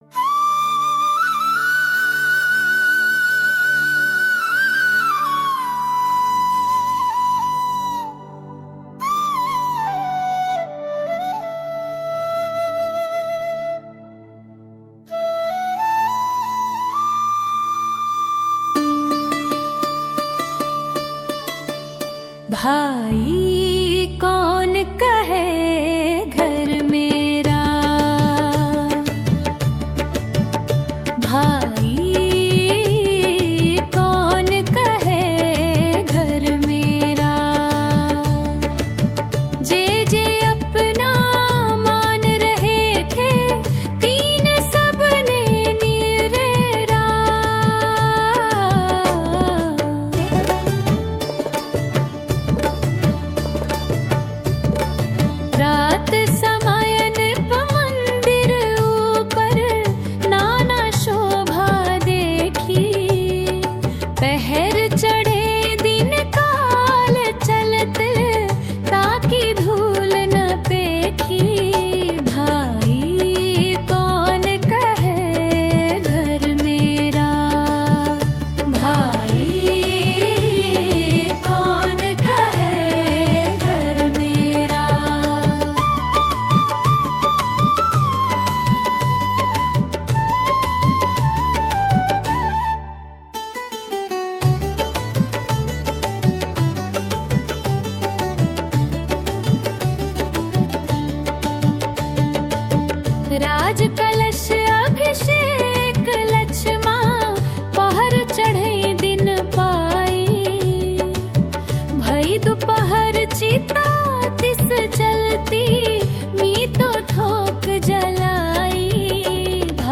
भाई कौन कहै घर मेरा | Bhai Kaun Kahe Ghar Mera - Bhakti - JinSwara Forum